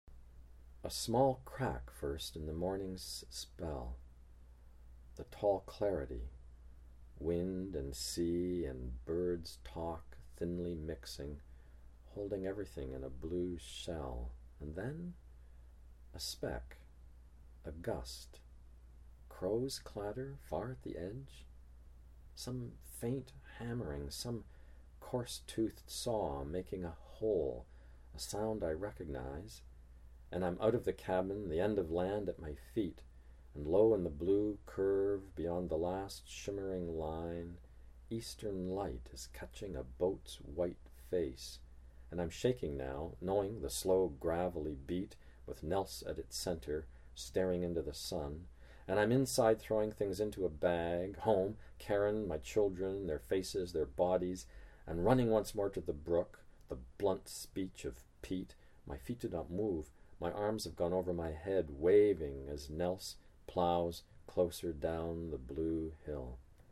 John Steffler reads [a small crack first in the morning's] from The Grey Islands